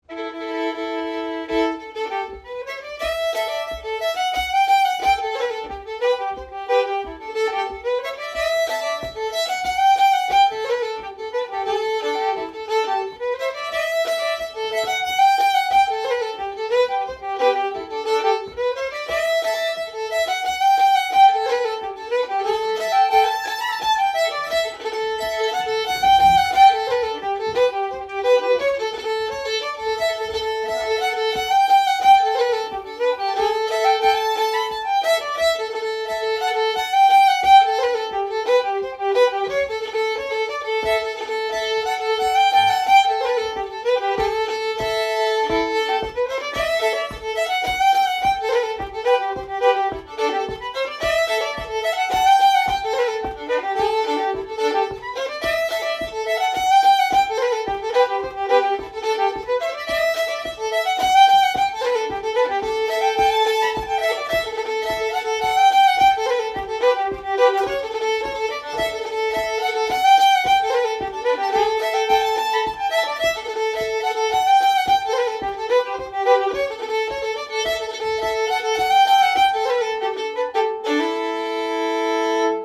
Sessions are open to all instruments and levels, but generally focus on the melody.
Composer Charlie McKerron Type Reel Key A modal Recordings